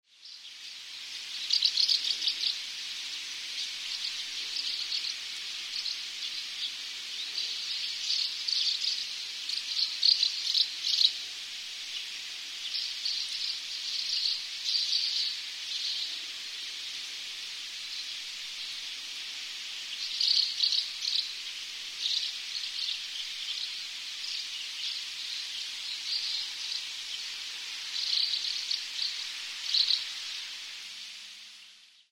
iwatsubame_c1.mp3